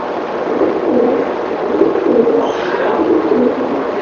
It was from the recorder that I mentioned that I had left upstairs during the investigation.
No one was upstairs while the recorder was running.
You will clearly hear the sounds of pigeons "Cooing" in the ceiling of the old building.
I believe I hear a mans voice mixed with the cooing of the birds.
pigeons.wav